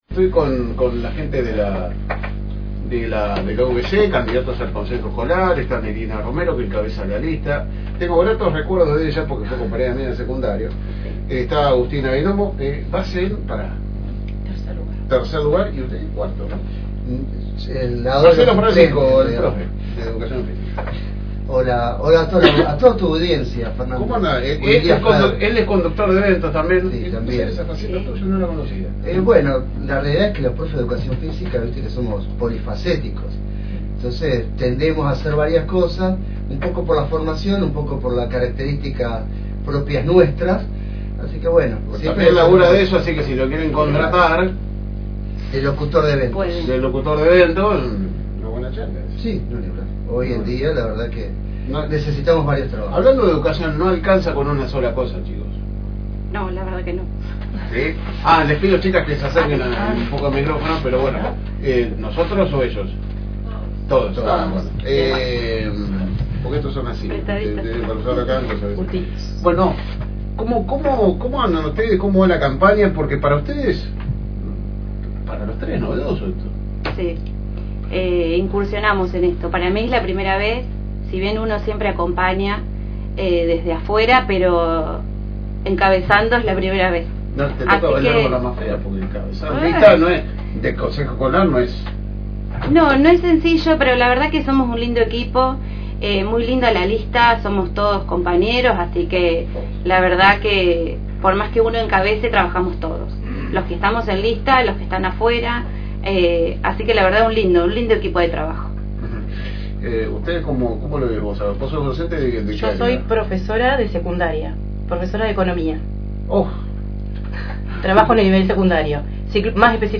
En la radio continuamos con los espacios dedicados a las y los políticos para conocer y que conozcan a los integrantes de las listas, que muchos y muchas de ellas es la primera elección como candidatas.